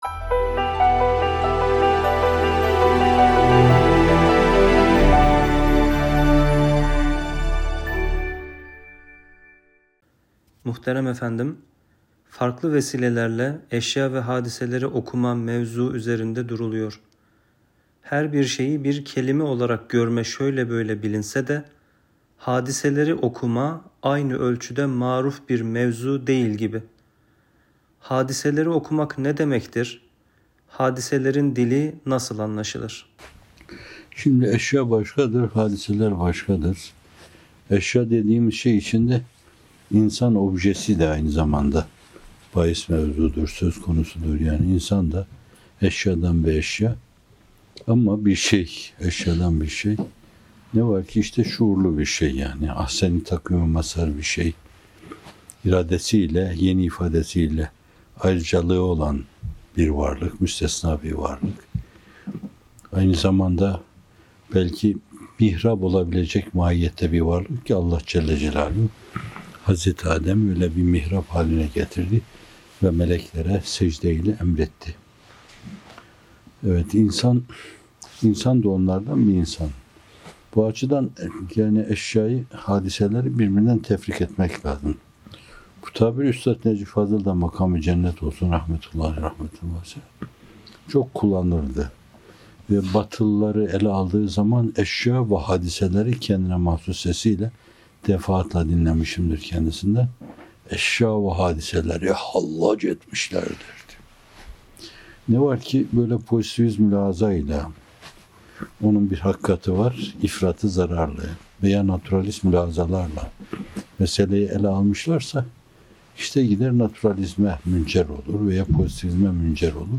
Bamteli Yeni – Hadiseleri Tevil ve Meşveret - Fethullah Gülen Hocaefendi'nin Sohbetleri